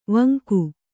♪ vanku